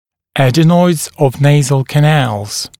[‘ædənɔɪdz əv ‘neɪzl kə’nælz][‘эдэнойдз ов ‘нэйзэл кэ’нэлз]аденоиды носовых каналов